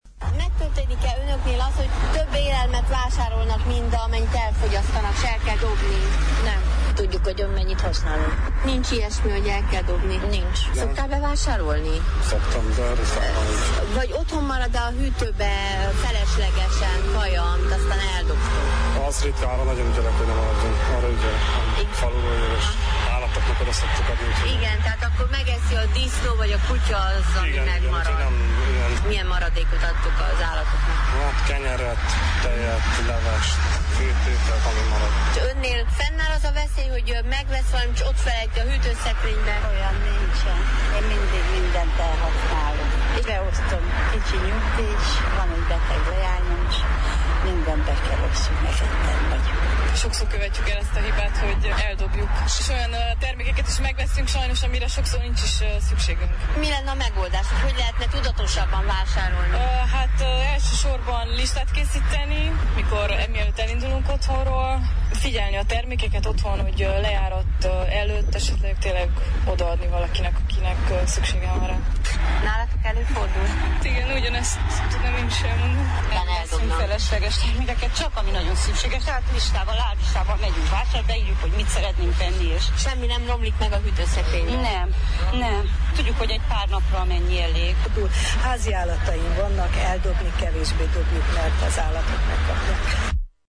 Vox-Élelmiszer.mp3